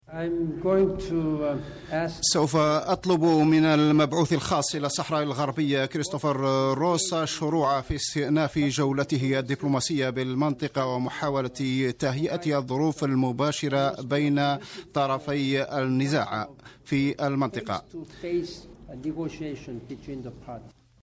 تصريح با كي مون بشأن زيارته إلى العيون المحتلة تصريح با ن كي مون بشأن استئناف روس جولته الدبلوماسية بالمنطقة الأمين العام للأمم المتحدة بان كي مون ( مترجم ) وزير الدولة وزير الشؤون الخارجية و التعاون الدولي رمطان لعمامرة